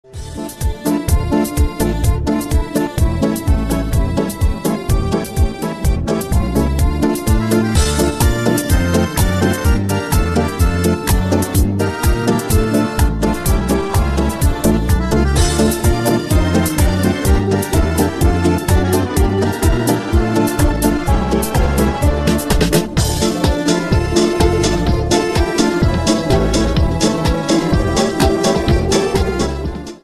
ретро , минус , оркестр